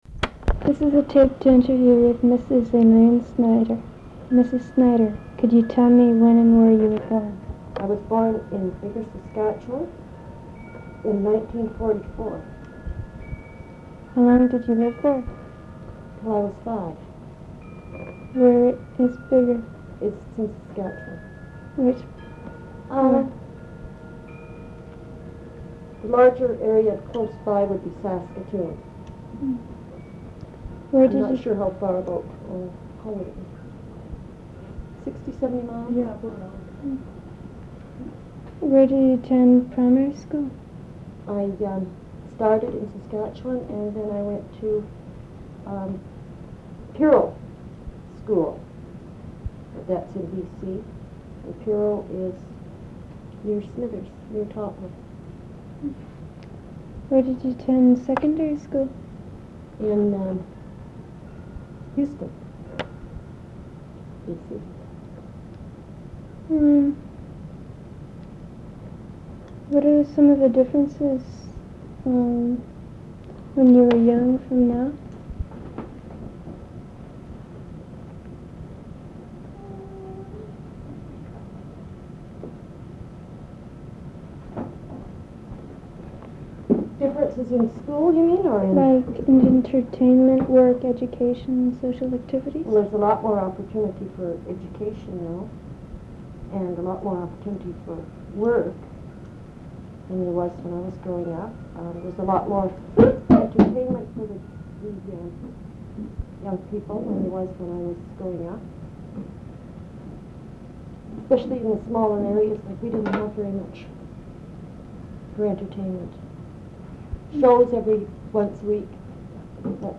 Audio non-musical
oral histories (literary works)